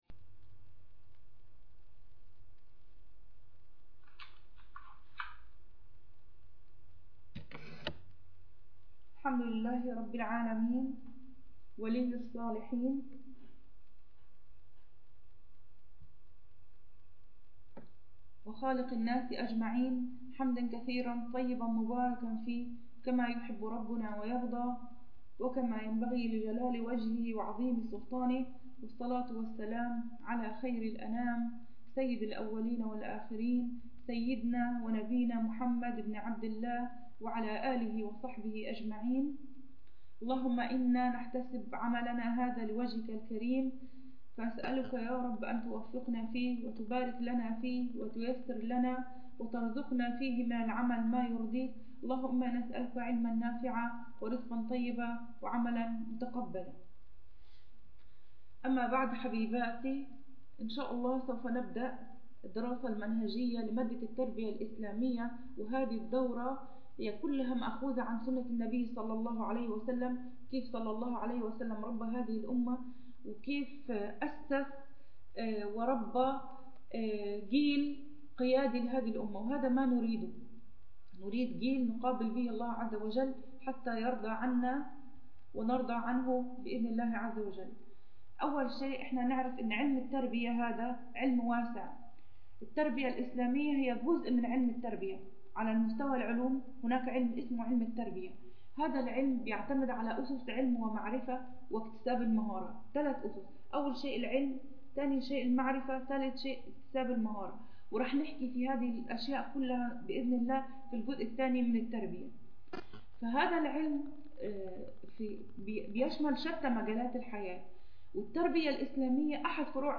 الدرس الثالث شرح متن الدرر اللوامع في أصل مقرإ الإمام نافع